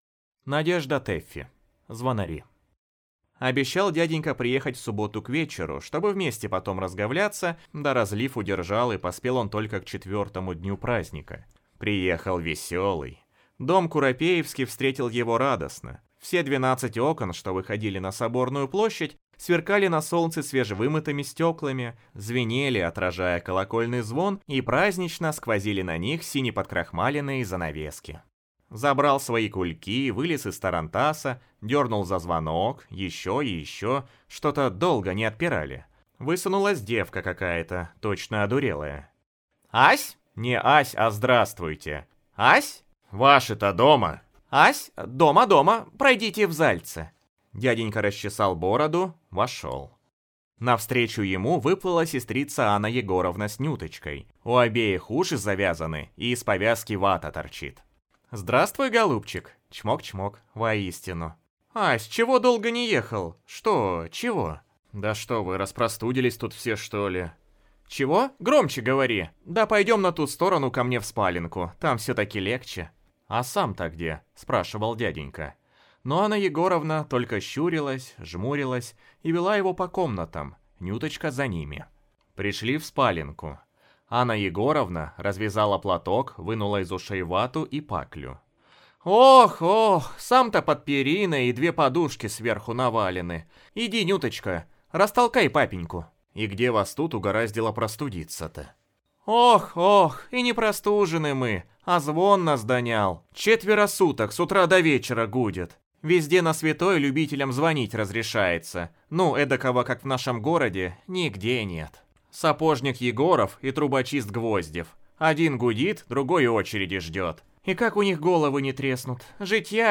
Аудиокнига Звонари | Библиотека аудиокниг